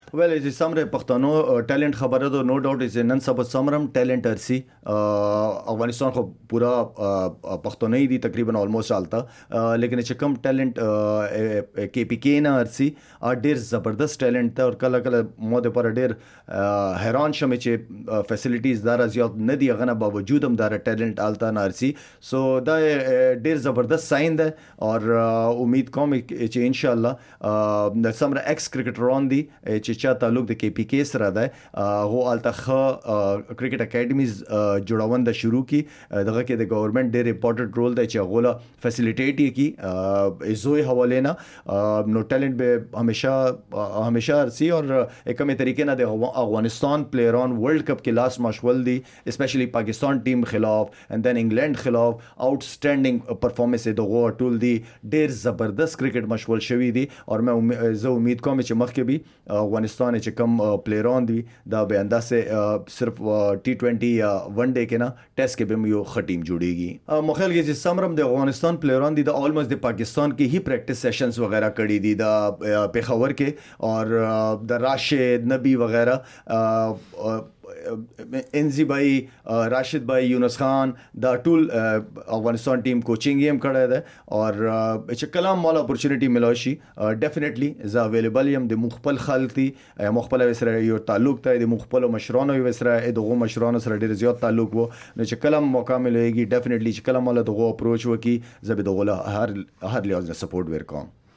شاهد افریدي له اس بي اس سره د خبرو پرمهال د افغانستان د ملي لوبډلې او د کرېکټ په دګر کې د پښتنو لوبغاړو د استعداد په اړه خپل نظر شریک کړی دی.